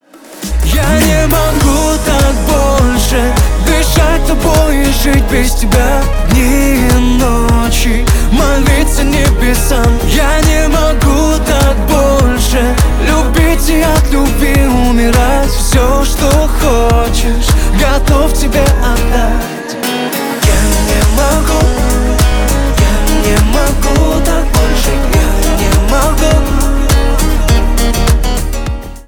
романтичные
поп